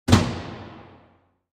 На этой странице представлена коллекция звуков рубильника — от четких металлических щелчков до глухих переключений.
Звук щелчка рубильника и вспышка света